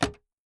DrawerDoorClose.wav